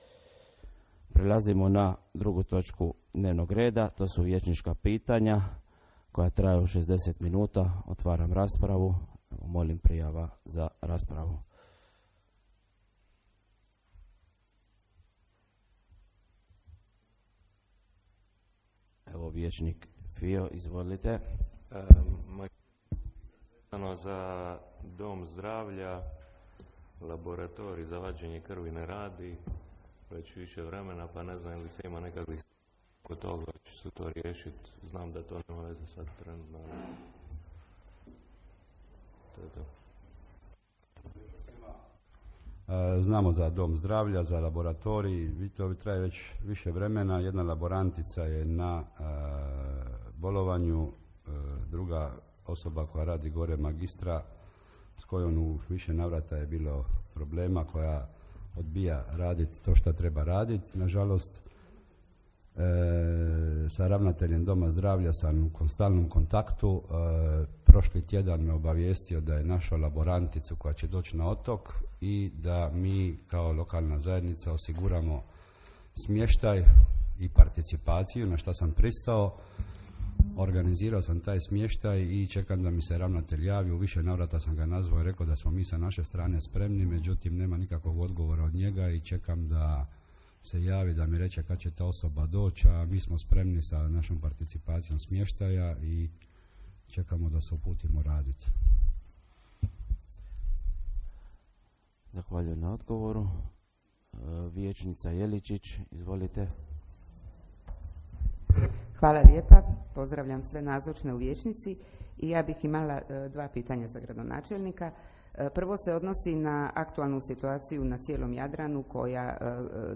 Snimka 3. sjednice Gradskog vijeća Grada Hvara | Grad Hvar